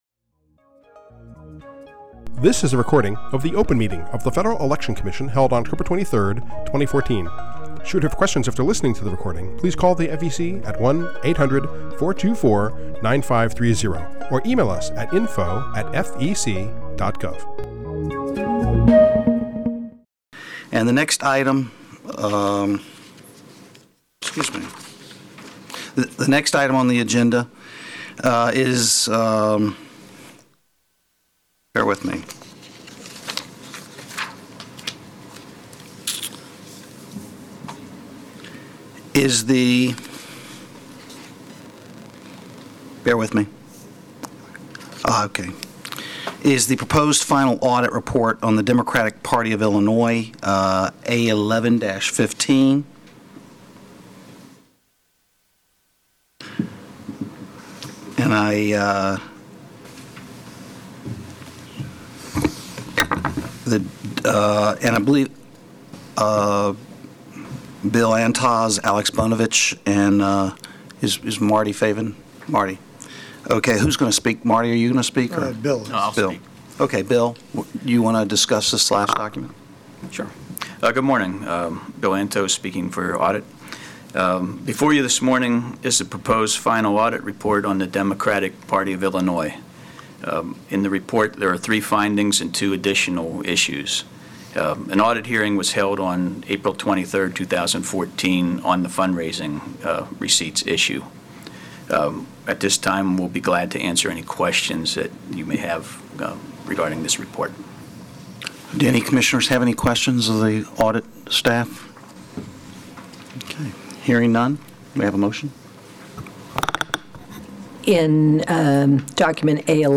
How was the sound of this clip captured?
October 23, 2014 open meeting